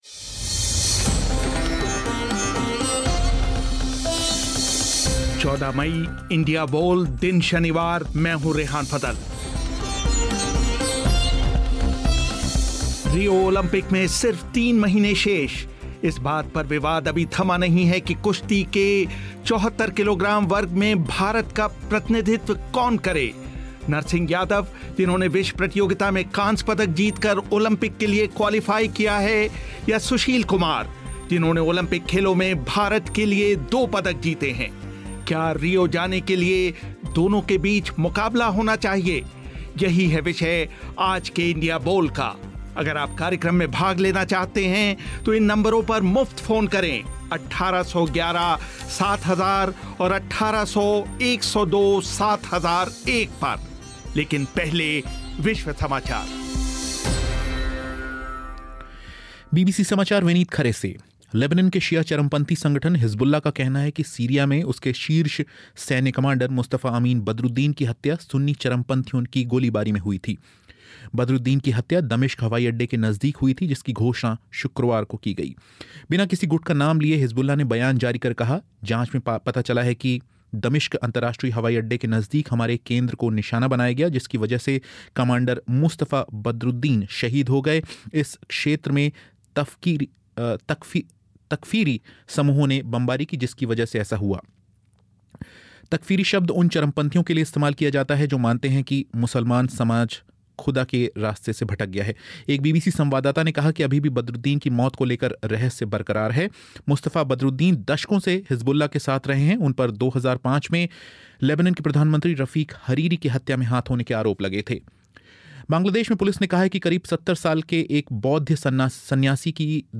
स्टूडियो में हमारे मेहमान हैं वरिष्ठ खेल पत्रकार